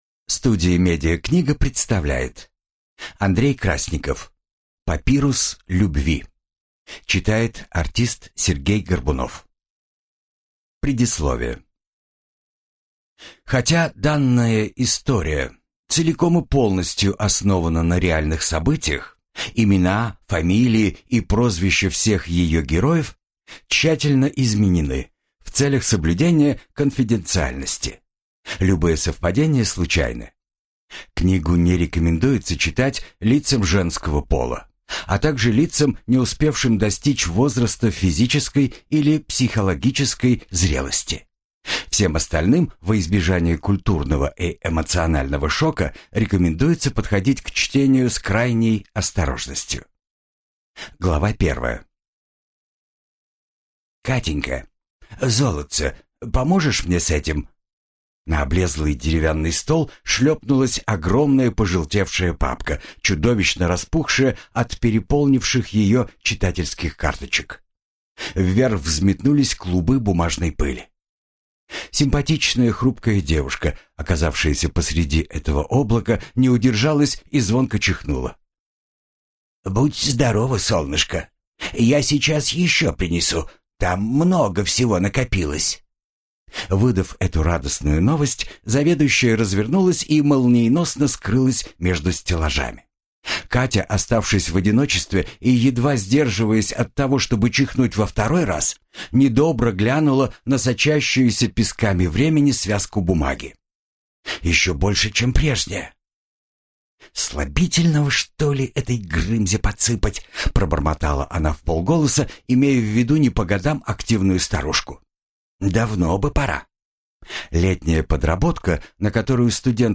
Аудиокнига Папирус любви | Библиотека аудиокниг